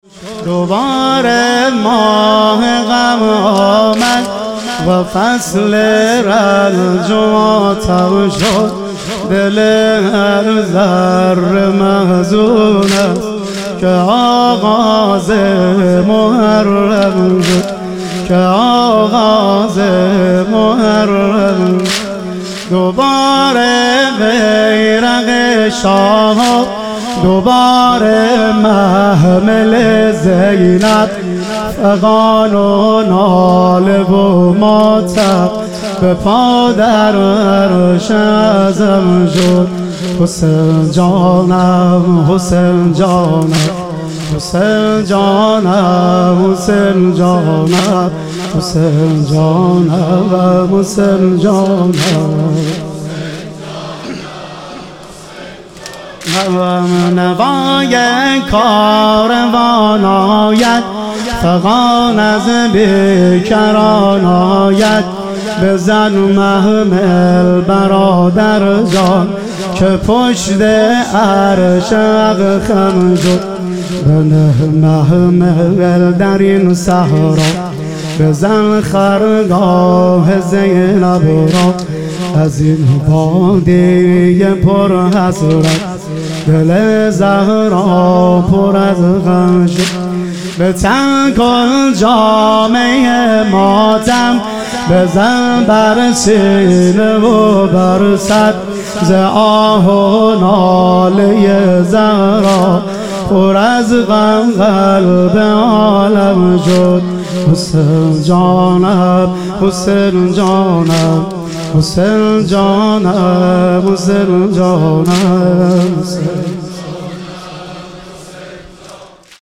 محرم الحرام - واحد